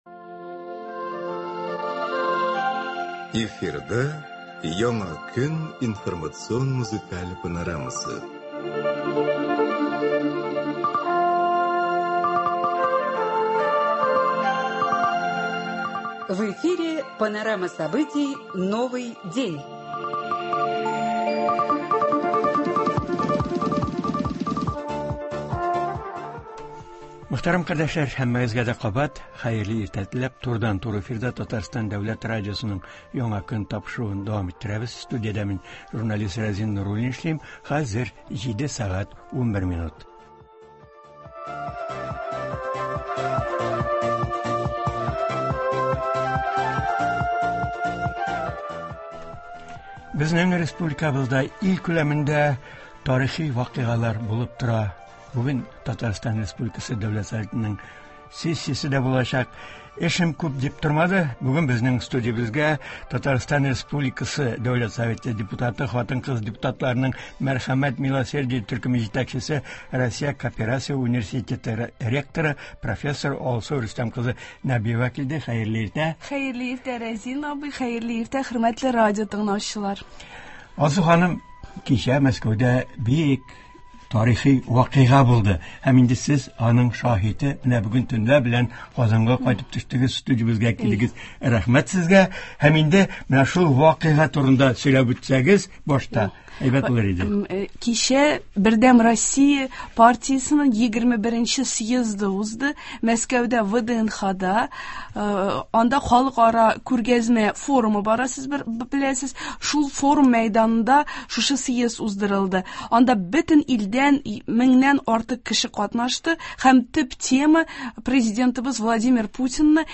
Узган атнада ил күләмендә дә, республикабызда да бөтен халкыбызны кызыксындырган мөһим вакыйгалар булды, ә бүген Татарстан Дәүләт Советының илленче утырышы үткәрелә. Болар хакында турыдан-туры эфирда Татарстан республикасы Дәүләт Советы депутаты, Мәскәү кооперация университеты ректоры, профессор Алсу Рөстәм кызы Нәбиева сөйләячәк һәм тыңлаучылар сорауларына җавап бирәчәк.